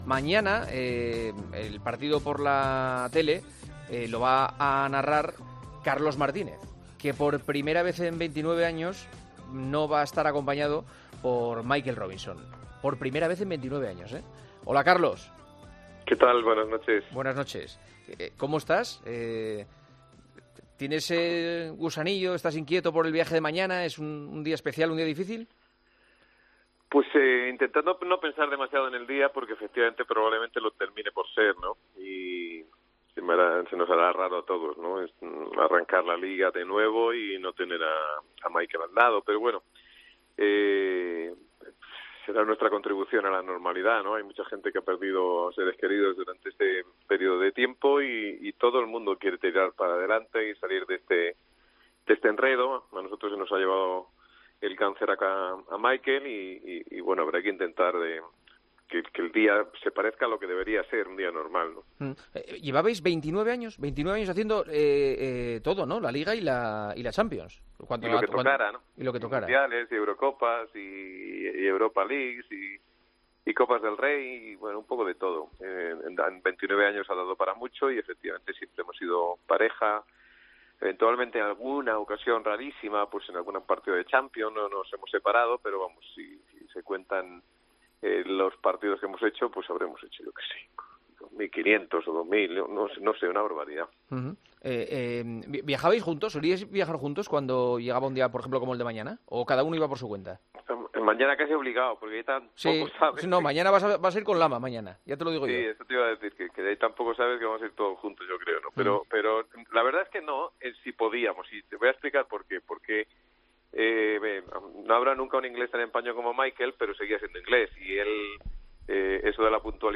Hablamos en El Partidazo de COPE con Carlos Martínez, narrador en Movistar que estará mañana en el Sevilla – Betis, primer partido en 29 años en el que no estará con Michael Robinson, recientemente fallecido debido a un cáncer.